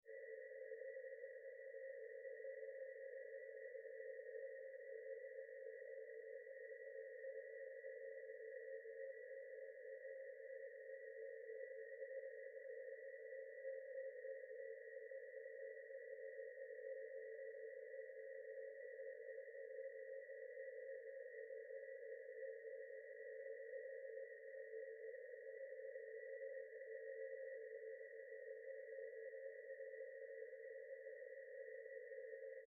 Звуки тумана
Здесь собраны записи, передающие мягкое дыхание туманного леса, шелест капель на паутине, приглушенные голоса природы.